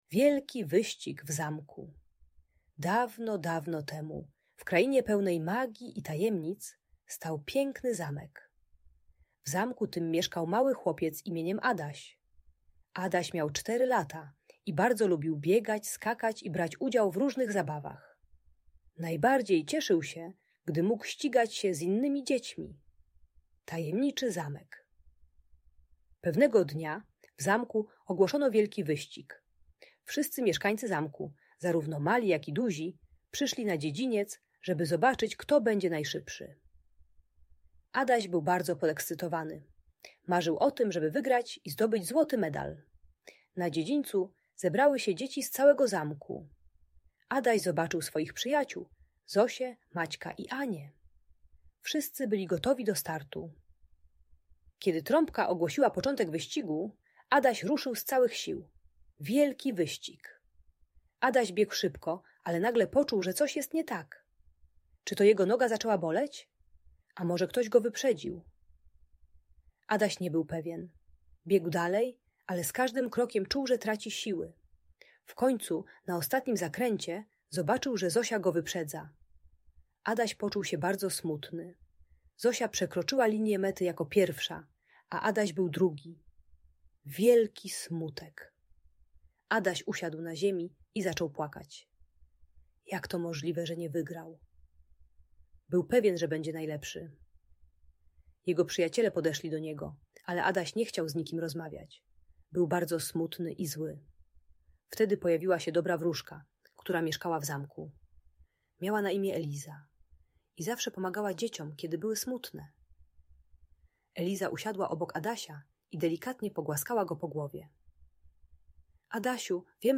Wielki Wyścig w Zamku - Audiobajka